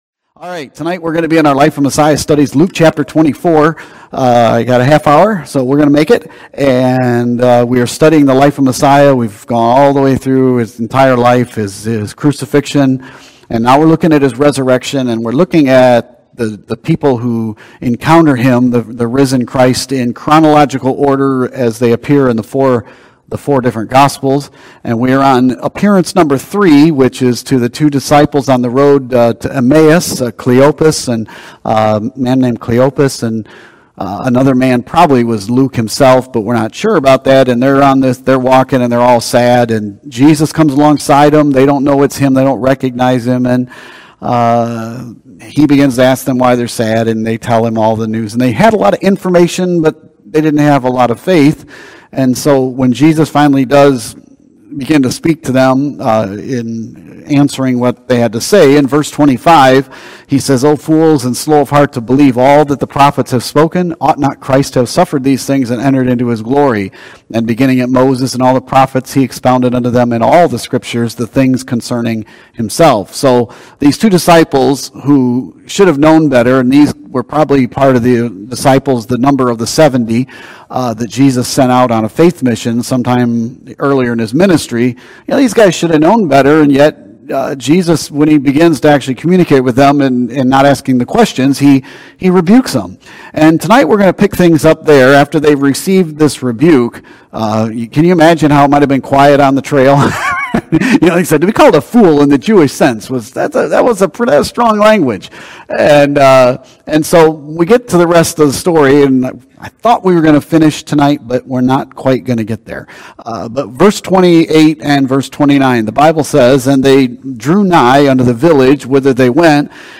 Sermons & Teachings | Open Door Baptist Church